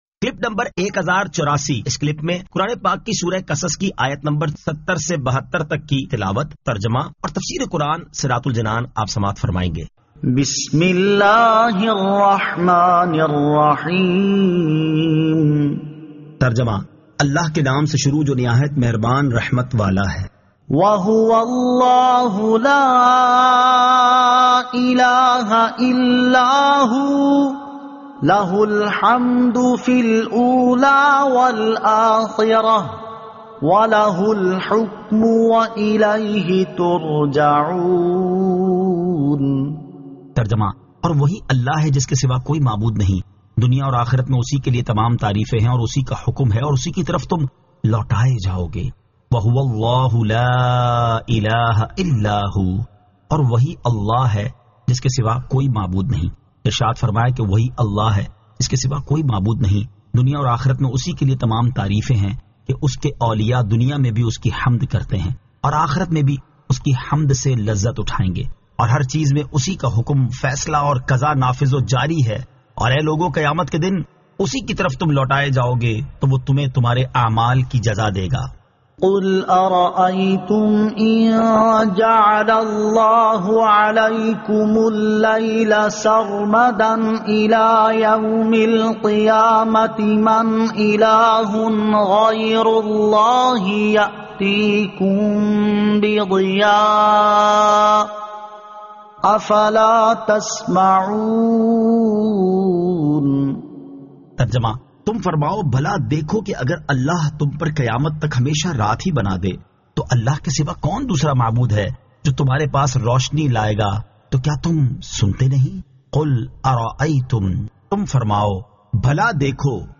Surah Al-Qasas 70 To 72 Tilawat , Tarjama , Tafseer